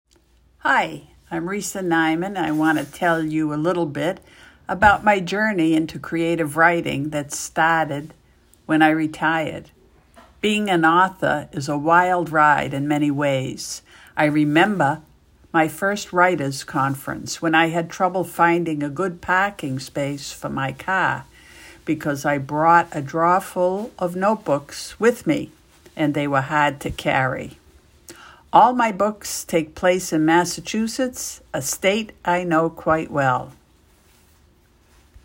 Click here to listen to my authentic Boston accent